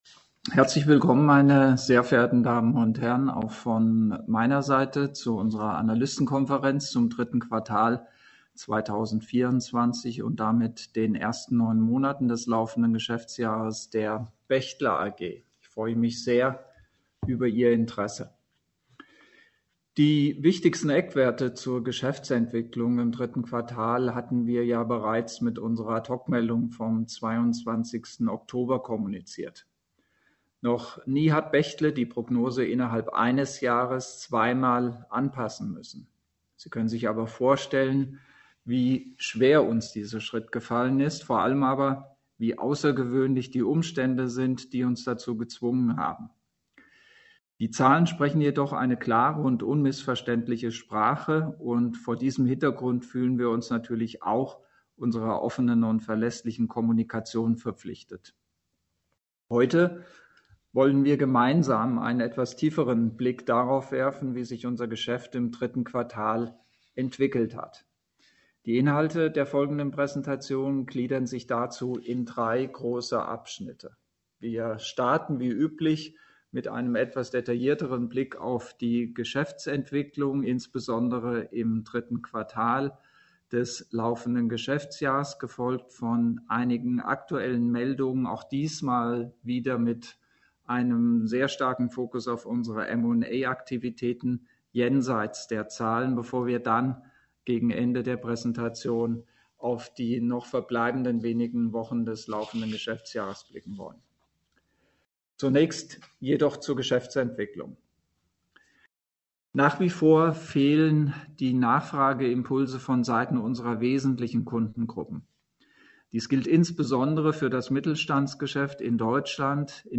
mitschnitt_analystenkonferenz_de_q3_2024.mp3